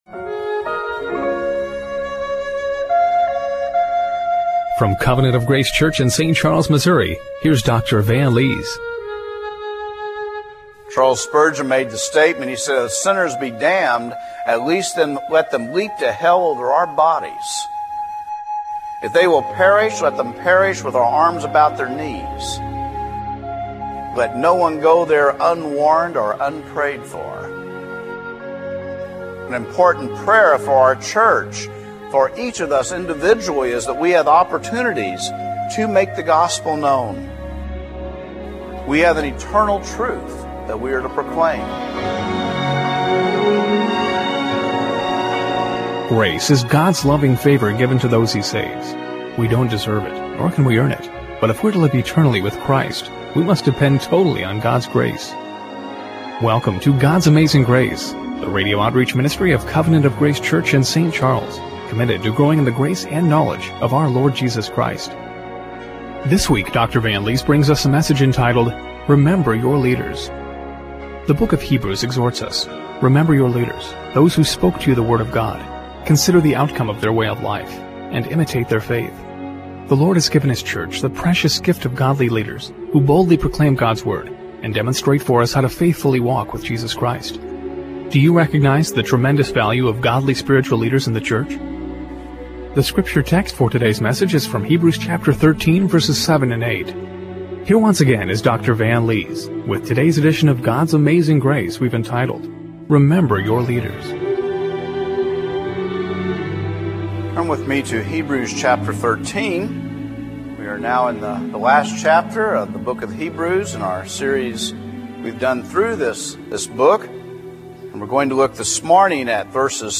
Hebrews 13:7-8 Service Type: Radio Broadcast Do you recognize the tremendous value of godly spiritual leaders in the church?